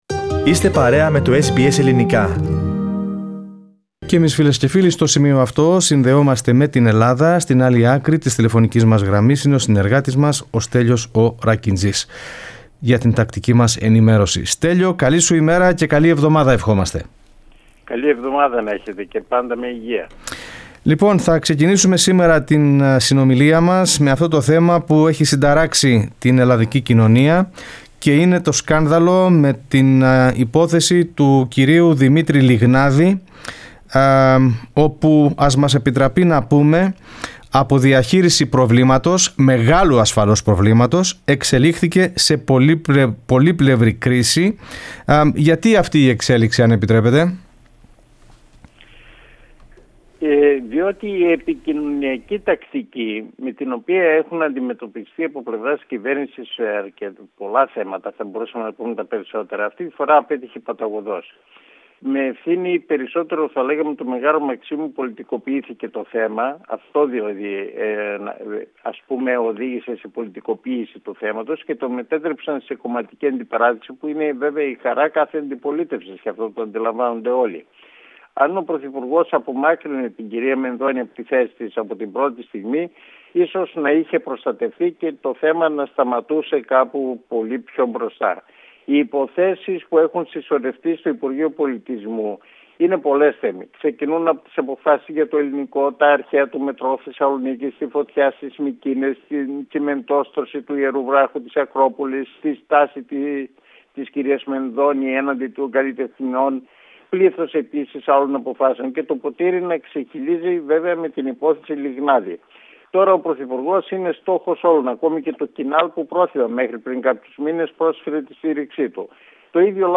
Το σκάνδαλο Λιγνάδη και τα Ελληνοτουρκικά στην ανταπόκριση από την Ελλάδα (22.02.2021)